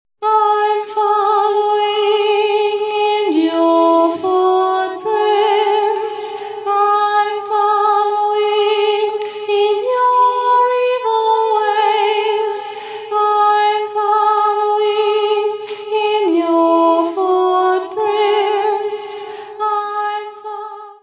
guitar, bass, samples
vocals
Music Category/Genre:  Pop / Rock